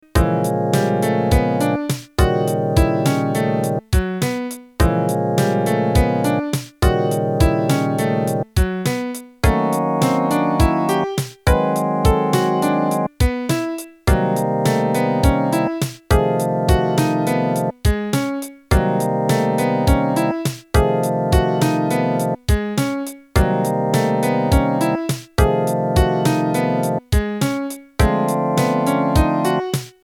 Glockenspiel��Tubular bells